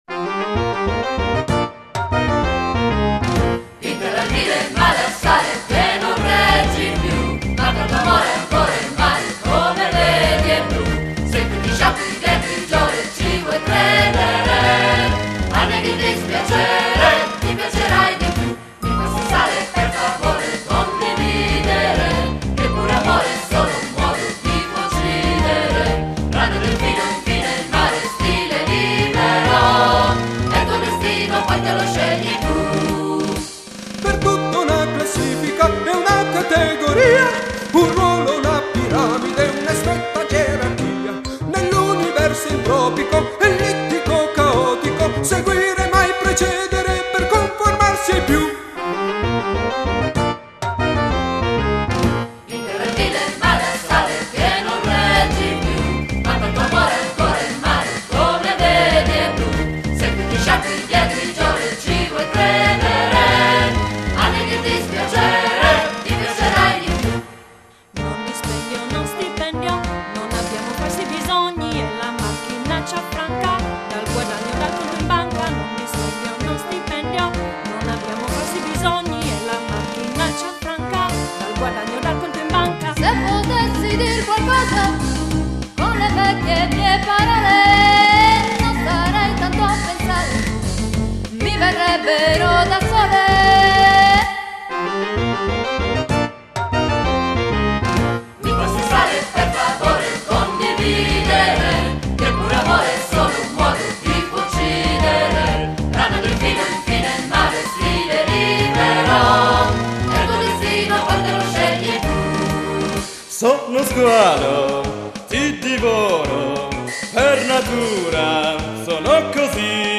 spettacolo musicale tragicomico (come tutto è)